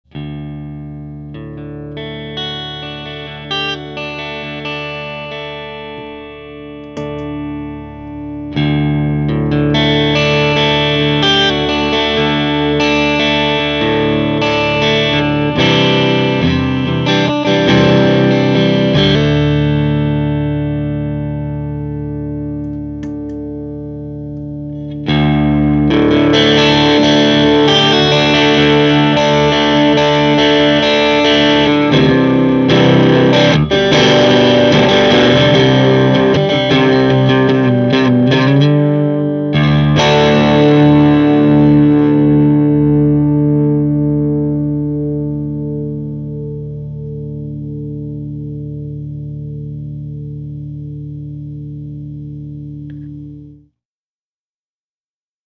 single coil sparkle/a little dirt
these samples were recorded using either an ibanez rg560 loaded with duncan hot rails and jb junior pickups or a crappy strat knockoff with unknown pickups running a homebrew single ended amp (12ax7 and 6550) and 12" openback cabinet. miced with a 57.